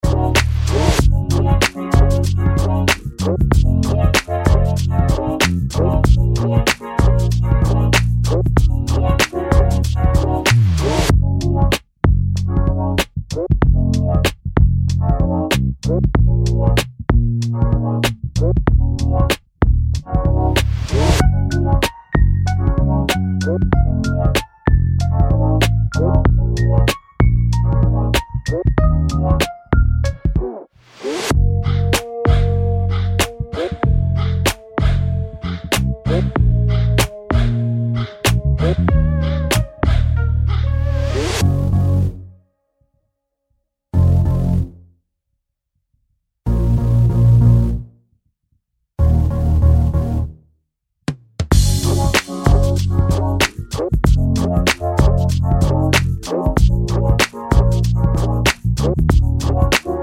no Backing Vocals R'n'B / Hip Hop 3:06 Buy £1.50